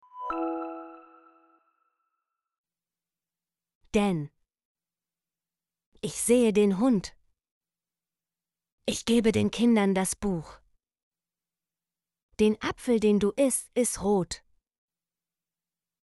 den - Example Sentences & Pronunciation, German Frequency List